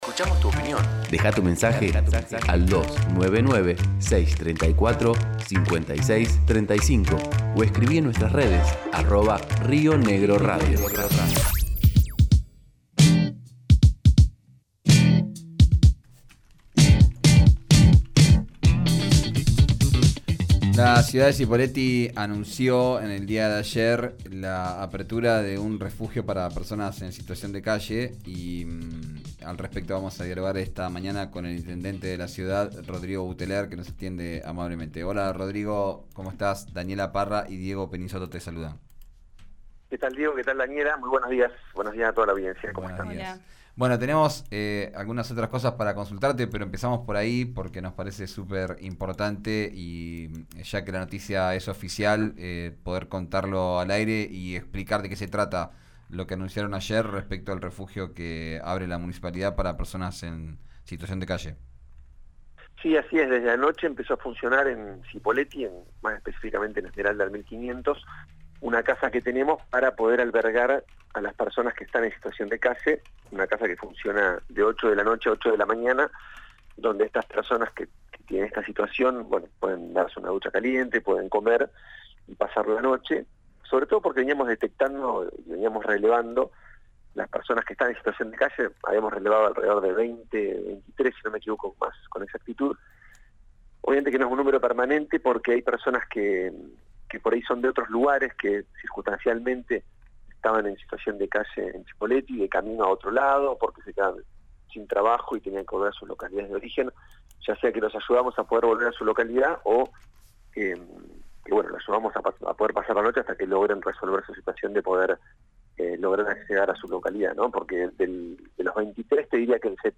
Escuchá a Rodrigo Buteler, intendente de Cipolletti, en RÍO NEGRO RADIO: